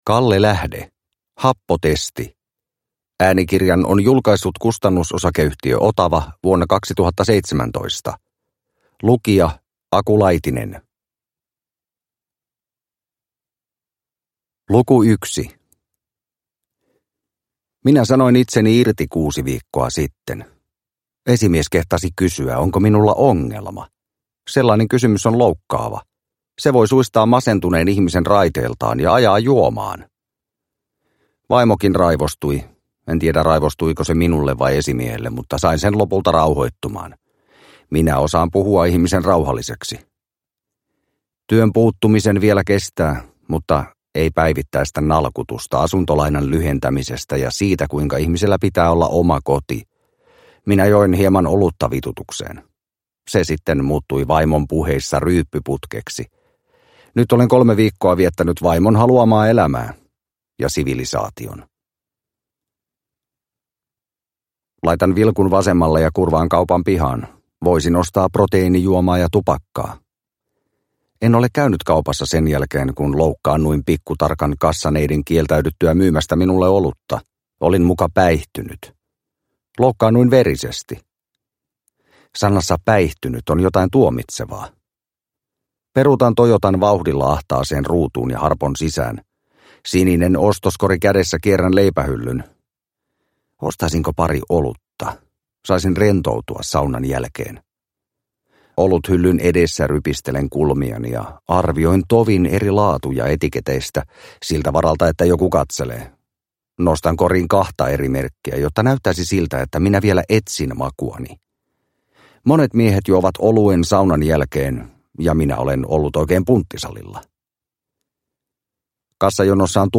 Happotesti – Ljudbok – Laddas ner